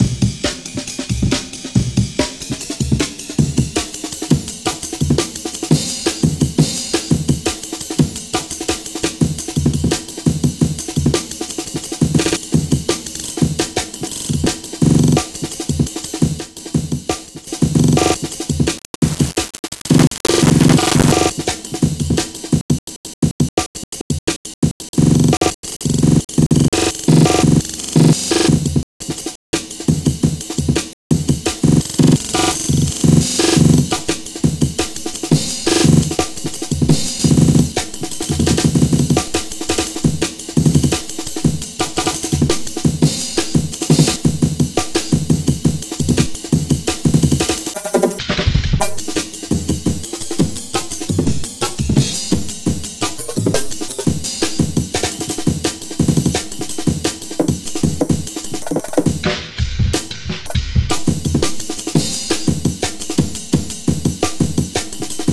A few examples of sliced audio this patch can make (made those on the first take without any external editing):
1. Amen Break